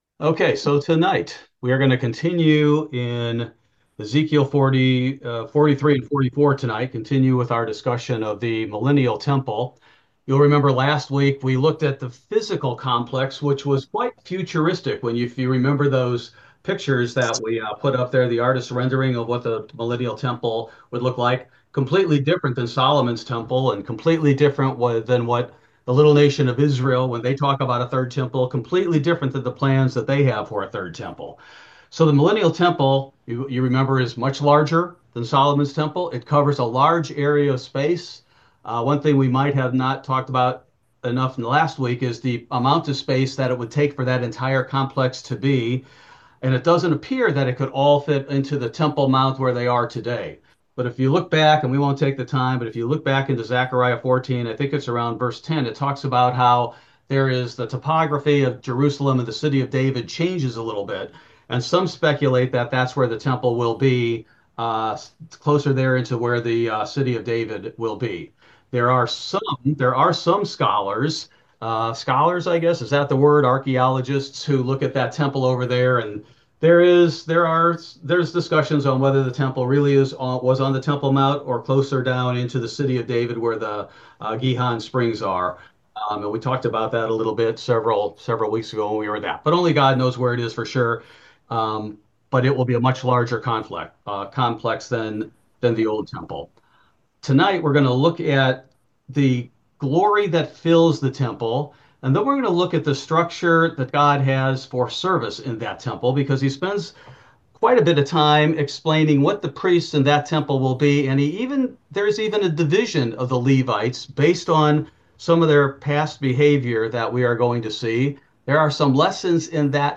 Ezekiel Bible Study: April 2, 2025